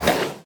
1.21.5 / assets / minecraft / sounds / mob / panda / bite2.ogg
bite2.ogg